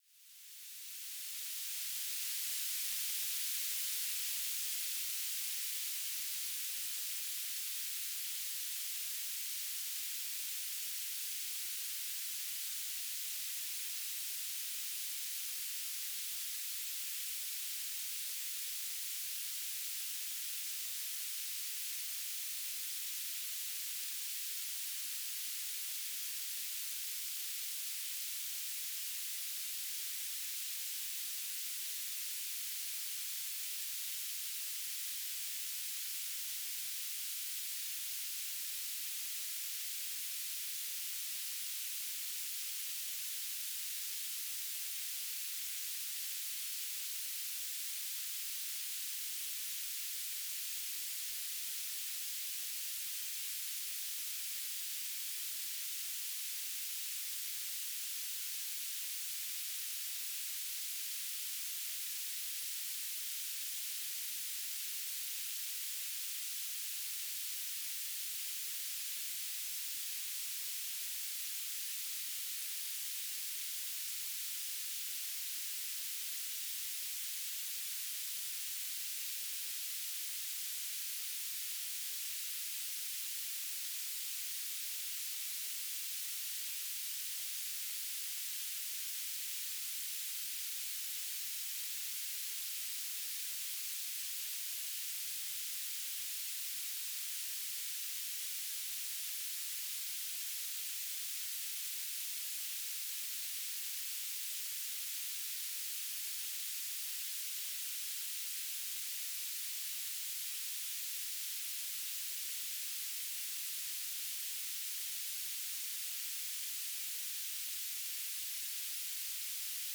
"transmitter_mode": "BPSK",